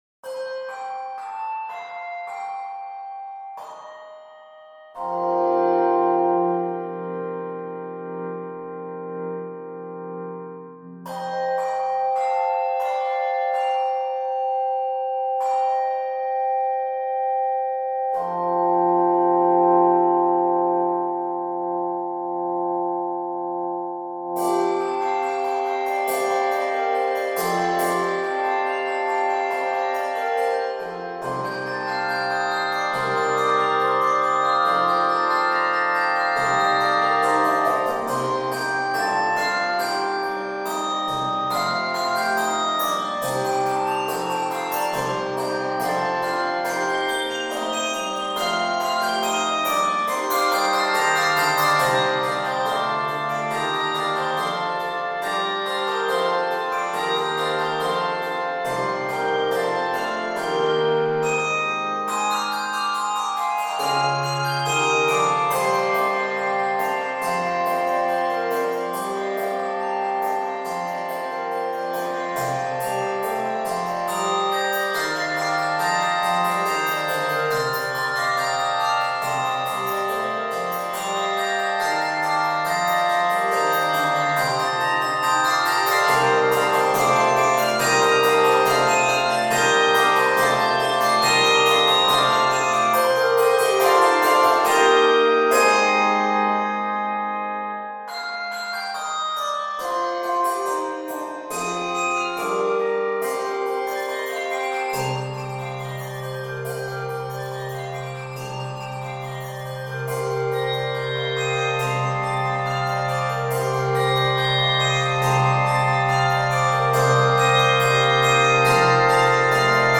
Traditional French carol